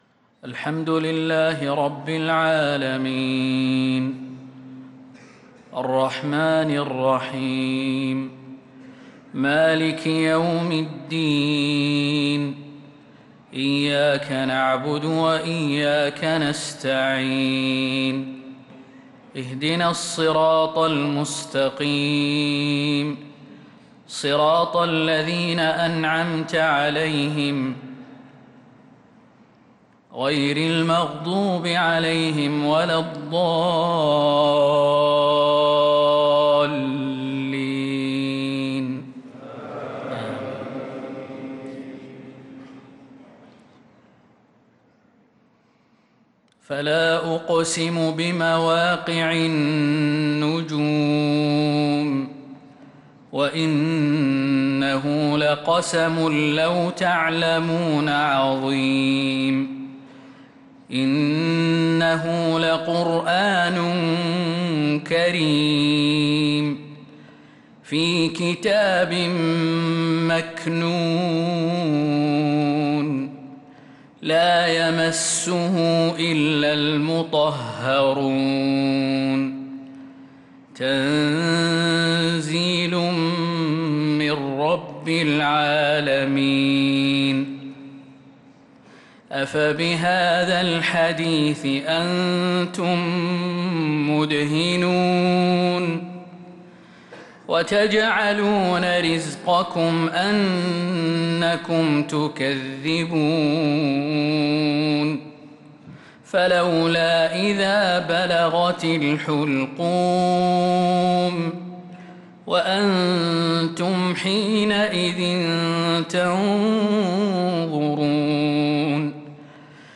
عشاء الأربعاء 1-7-1446هـ خواتيم سورة الواقعة 75-96 | isha prayer from Surat al-Waqi`ah 1-1-2025 > 1446 🕌 > الفروض - تلاوات الحرمين